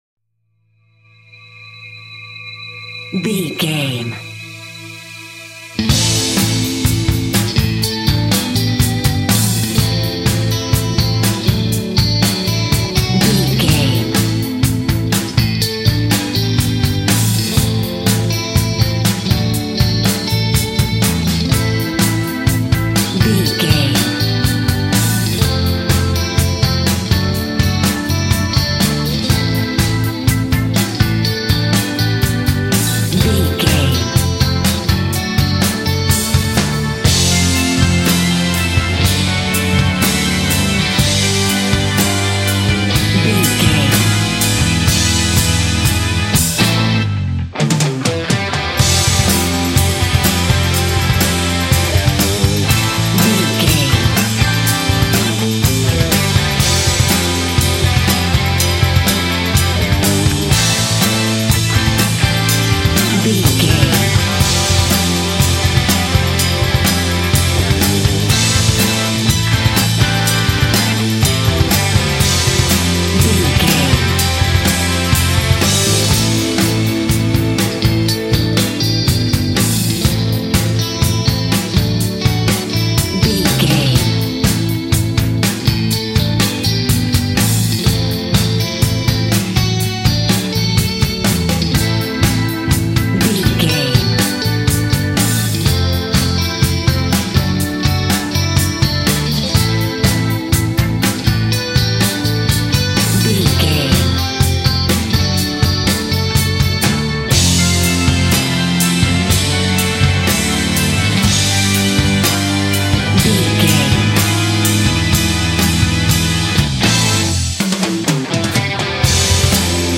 Aeolian/Minor
drums
electric guitar
electric organ
hard rock
aggressive
energetic
intense
nu metal
alternative metal